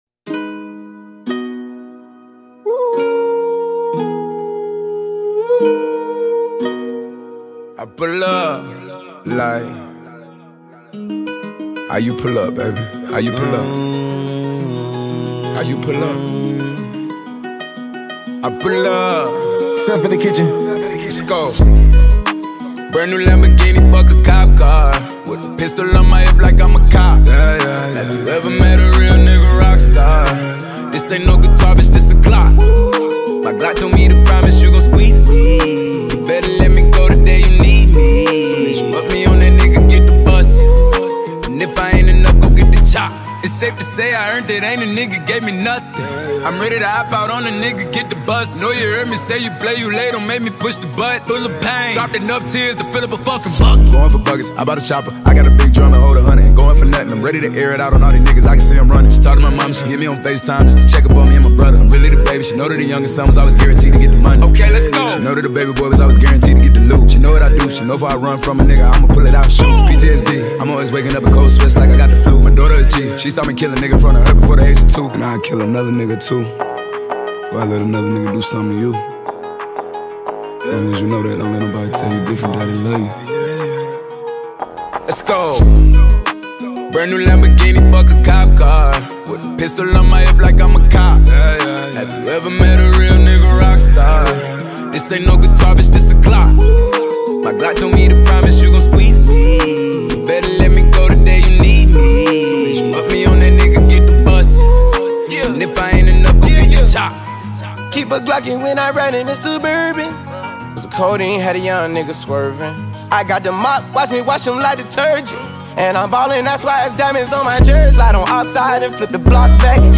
With its high energy tempo and catchy sounds